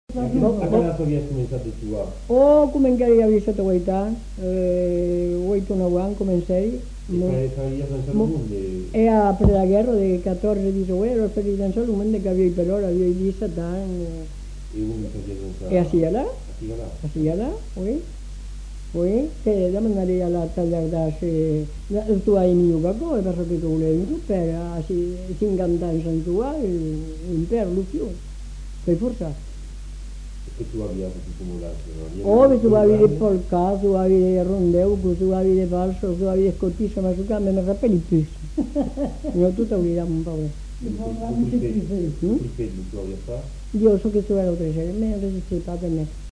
Aire culturelle : Haut-Agenais
Lieu : Cancon
Genre : récit de vie